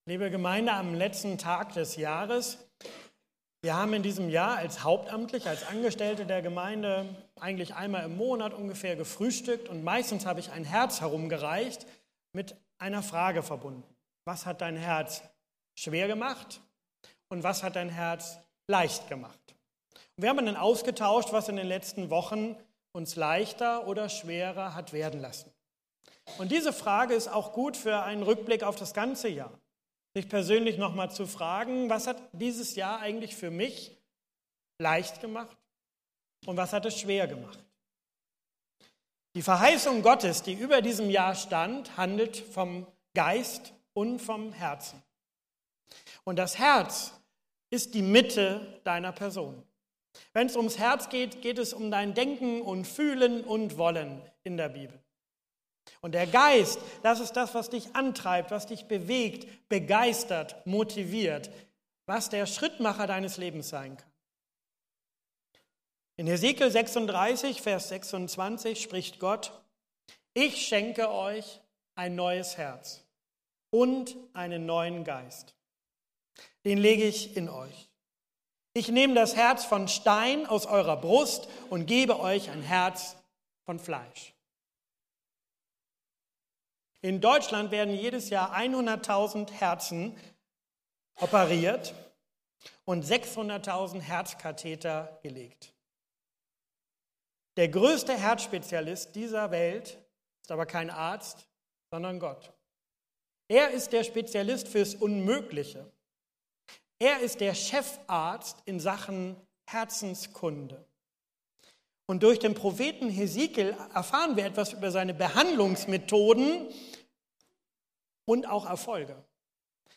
Jahresabschlussgottesdienst